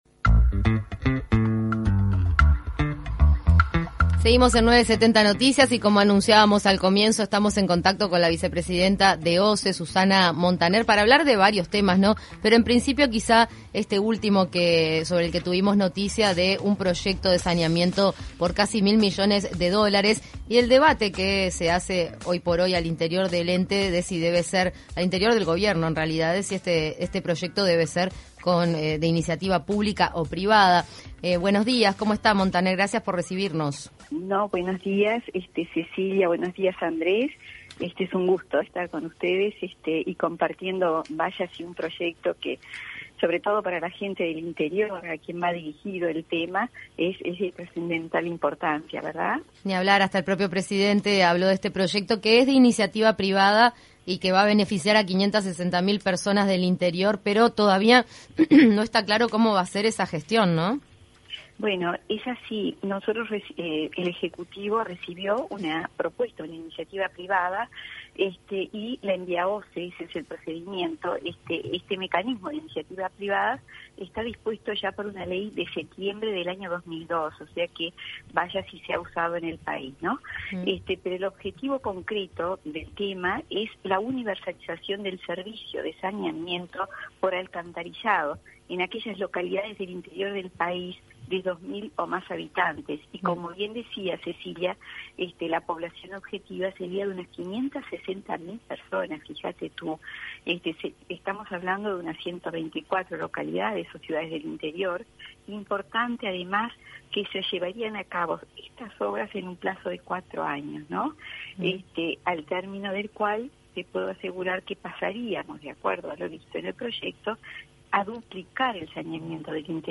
En entrevista con 970 Noticias Primera Edición, la vicepresidenta de la institución, Susana Montaner, explicó que hay «como mucho» un 50% de saneamiento y quiere incrementarse a un 88%.